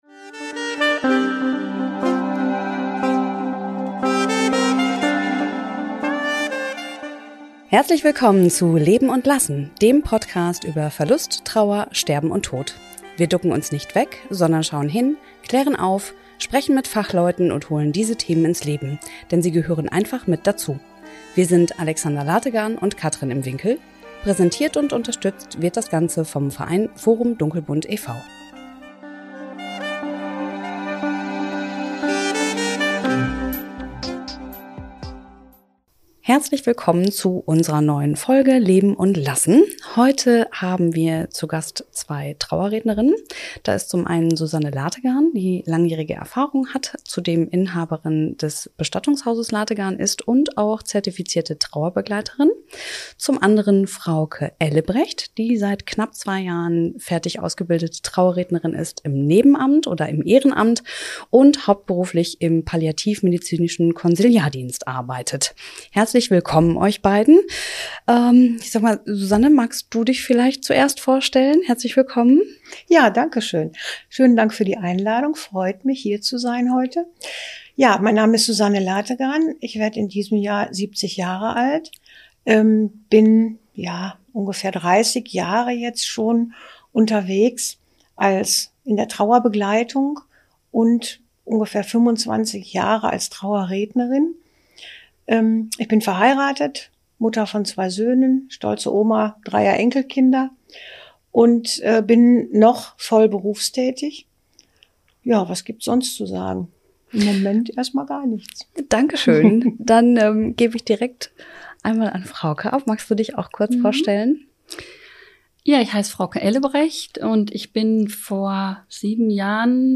Heute haben wir zwei Trauerrednerinnen zu Gast.